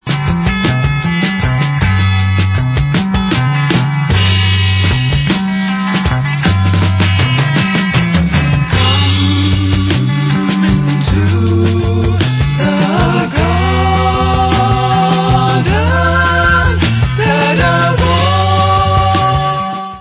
LOST CLASSIC OF 60'S UK ACID FOLK/BAROQUE PSYCHEDELICA